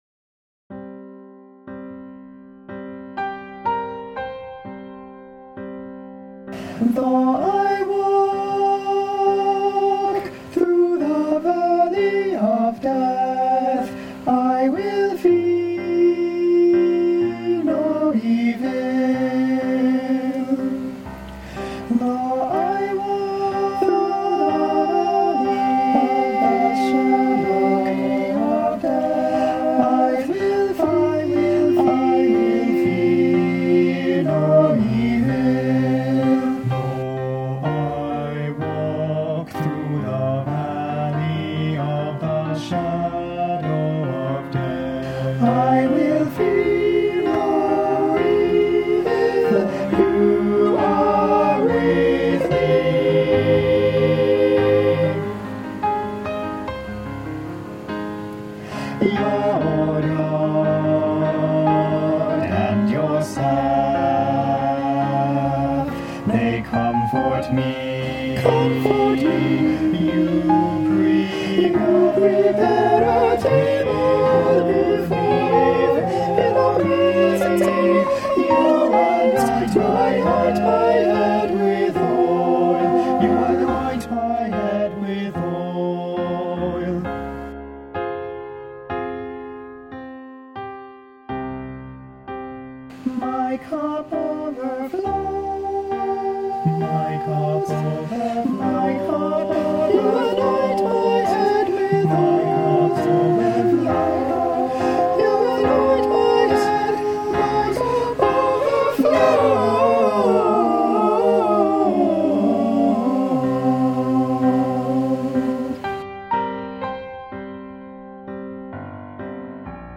O Magnum Mysterium" (SAB, Piano)
Commissioned for First Baptist Church Madison choir (2017)